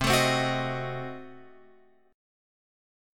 Cdim7 chord